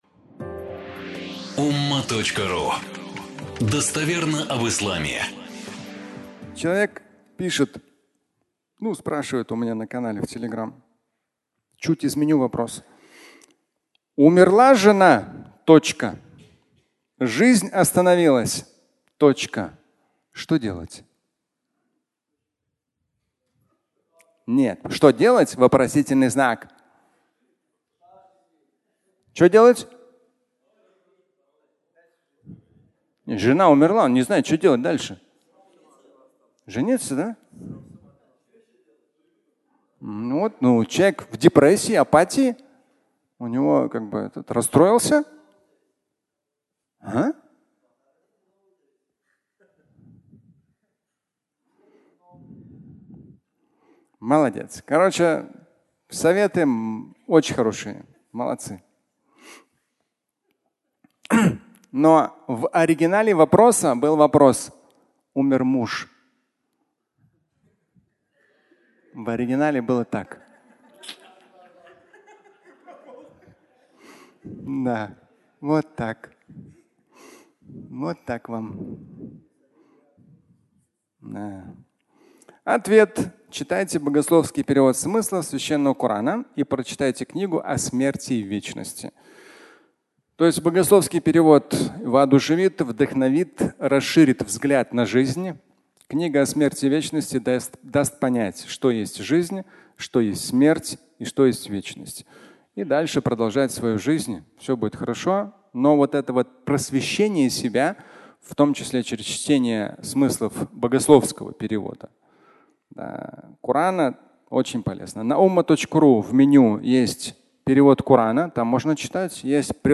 Умерла жена (аудиолекция)